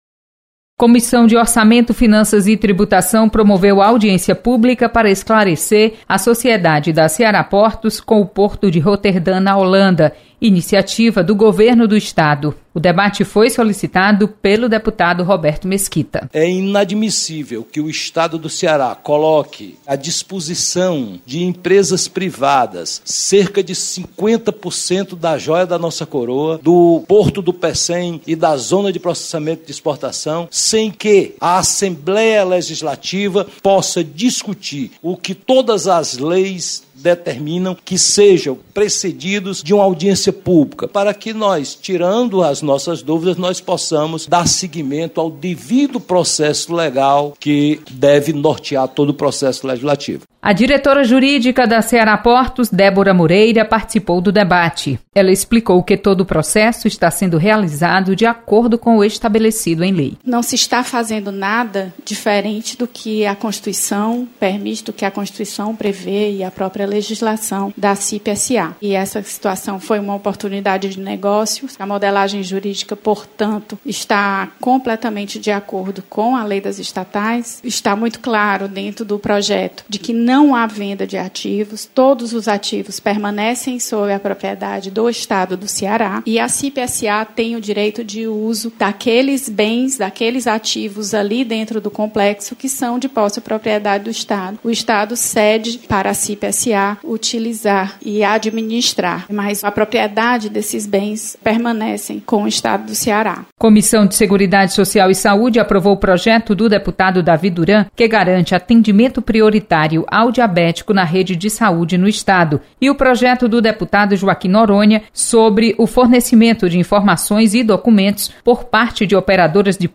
Acompanhe o resumo das comissões técnicas permanentes da Assembleia Legislativa. Repórter